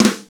Index of /90_sSampleCDs/Northstar - Drumscapes Roland/DRM_Fast Rock/SNR_F_R Snares x